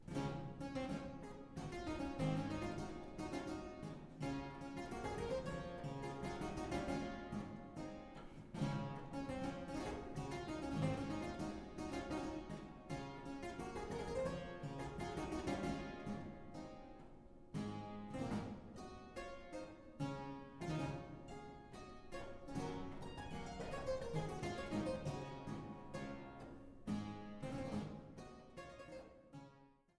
Fortepiano und Clavichord